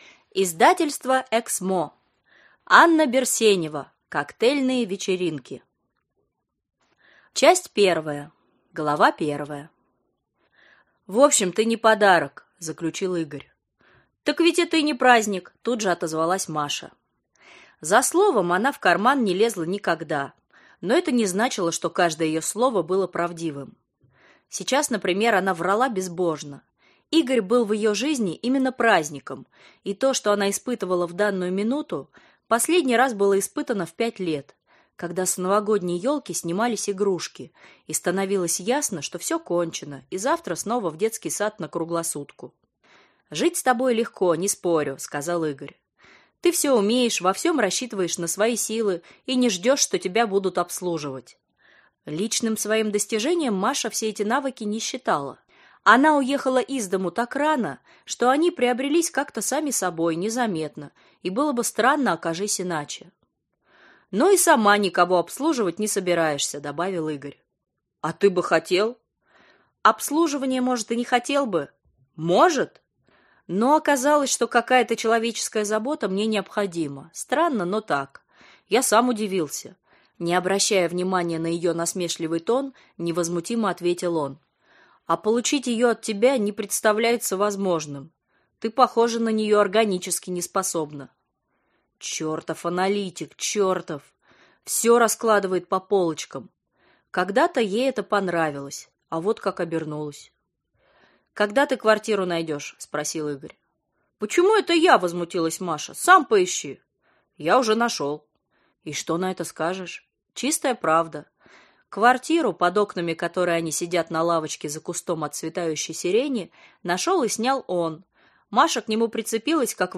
Аудиокнига Коктейльные вечеринки | Библиотека аудиокниг